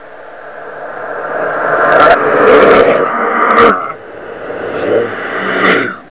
deadsignal6.wav